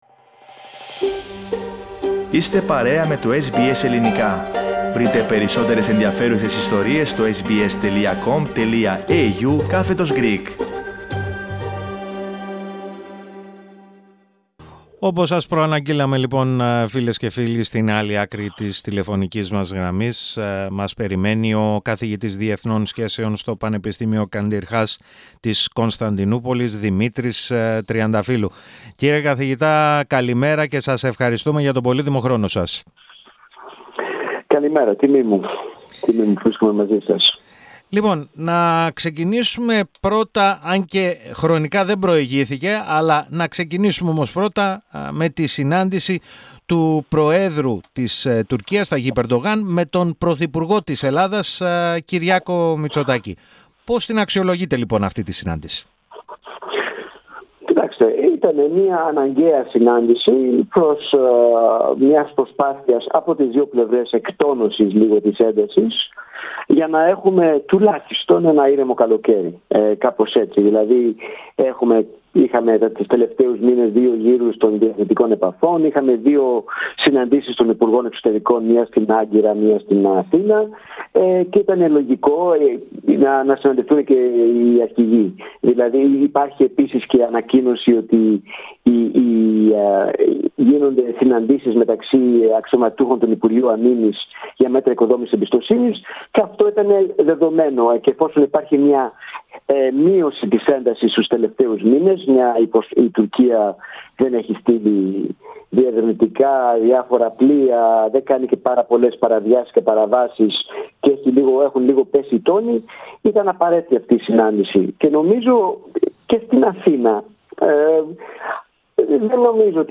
Ακούστε, ολόκληρη τη συνέντευξη, πατώντας το σύμβολο στο μέσο της κεντρικής φωτογραφίας.